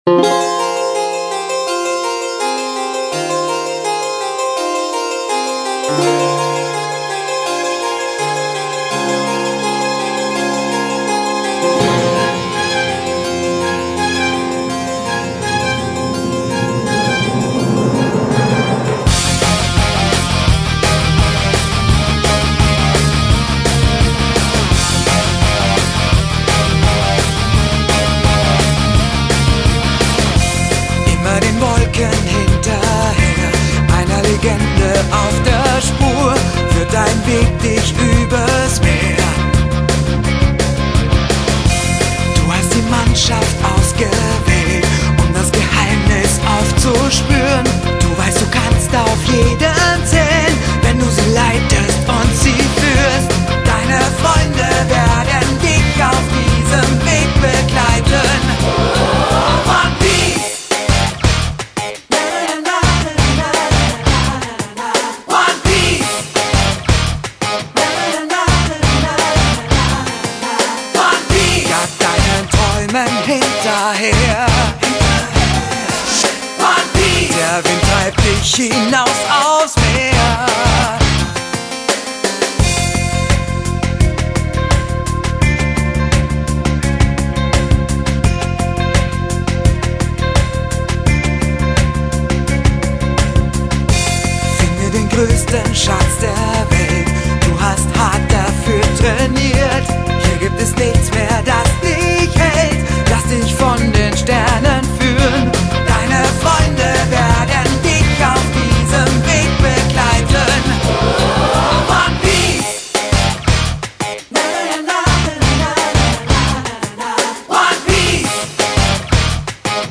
TV Series
Group Singing